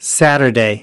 7.Saturday  /ˈsæt̬.ɚ.deɪ/ : thứ bảy